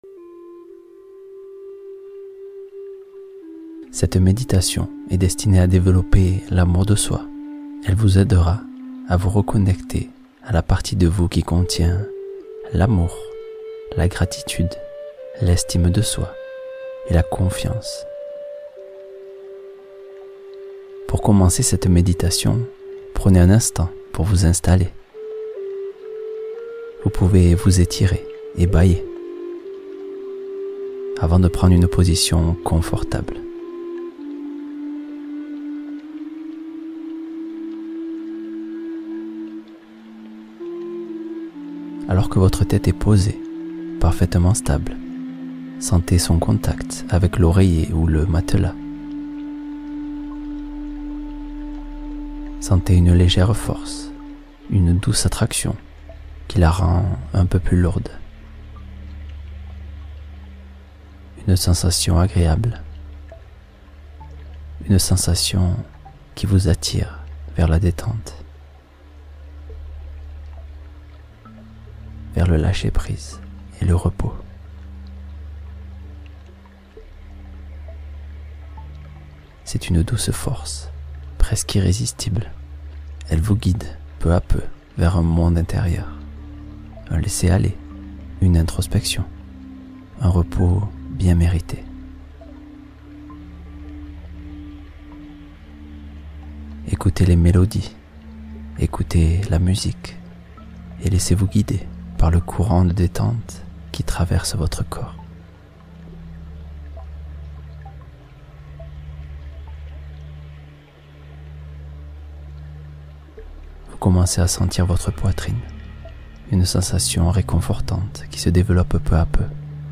S’aimer pleinement : méditation guidée pour réveiller l’amour de soi